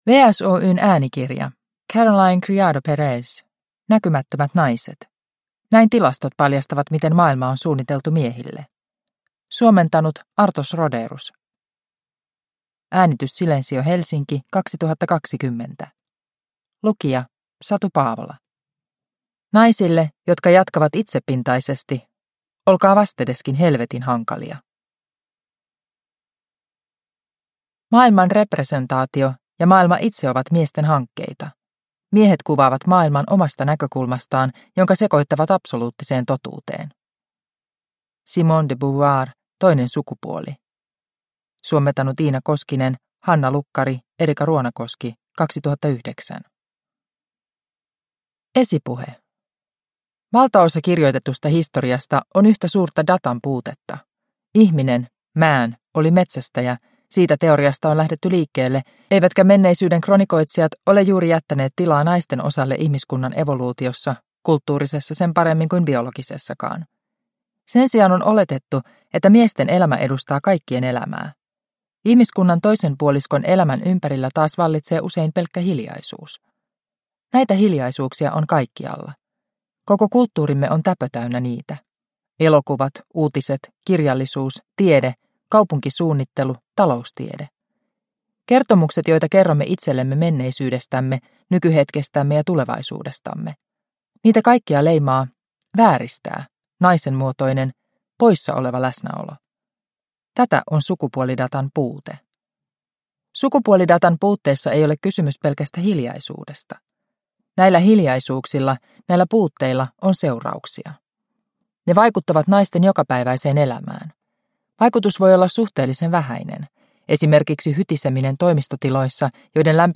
Näkymättömät naiset – Ljudbok – Laddas ner